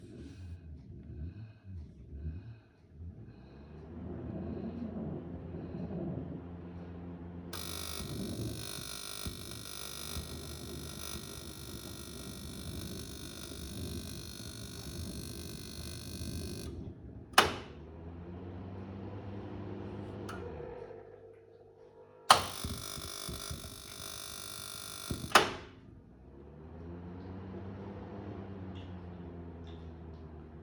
Juste un bruit étrange au démarrage, j'essaye de vous l'envoyer.
Bruit au démarrage poêle à pétrole.mp3
Le bruit étrange ne semble pas si étrange que cela, c'est le bruit d'un arc électrique certainement destiné à l'allumage du brûleur.
bruit-au-demarrage-poele-a-petrole.mp3